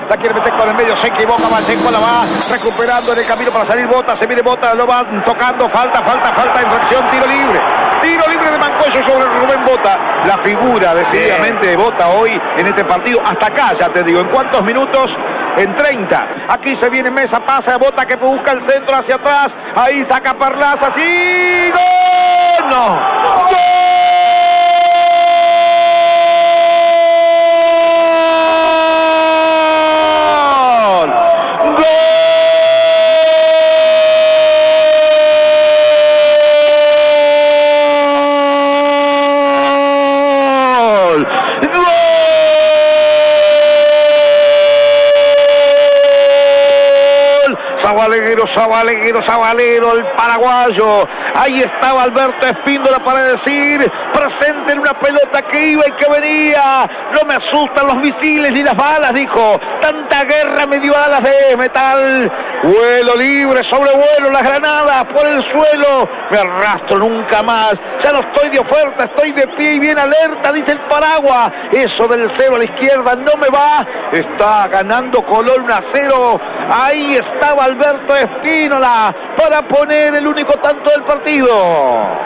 GOL-1-COLON.mp3